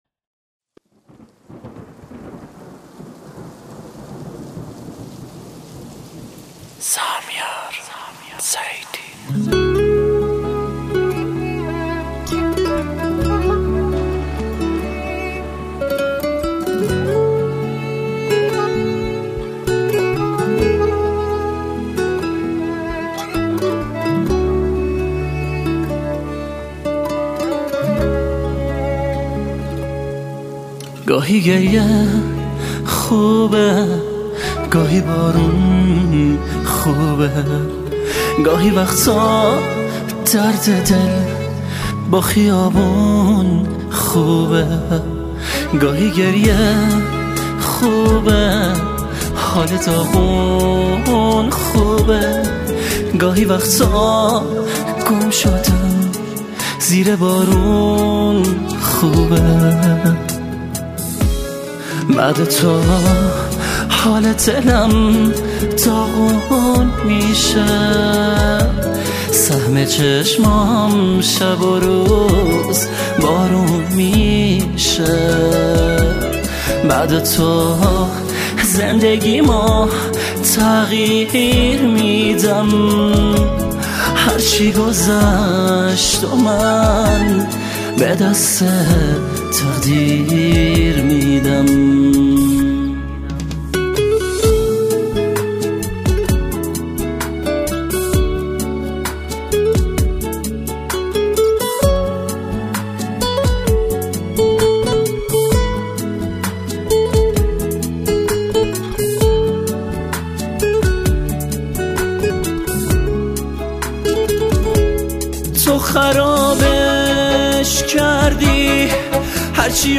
گیتار
ویولن .